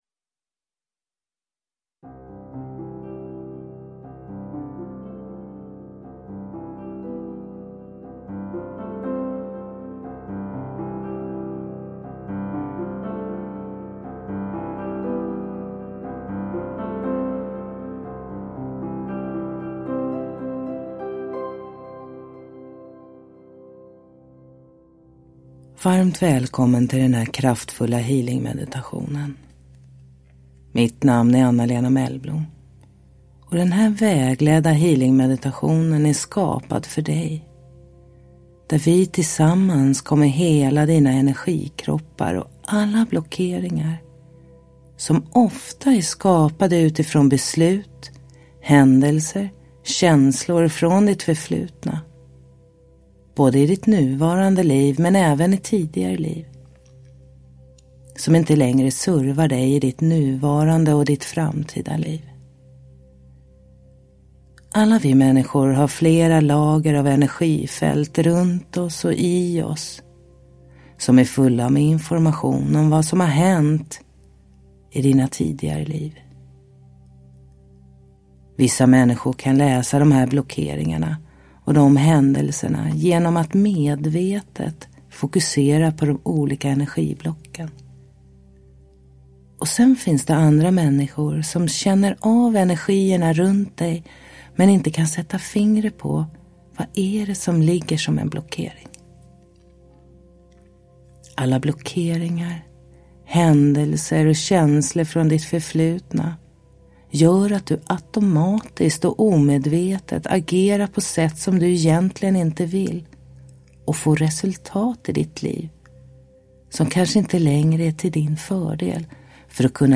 Downloadable Audiobook
Denna inspelning innehåller Alfavågor
Sound effects